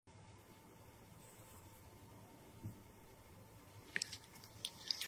Satisfying AI ASMR Pimple Popper!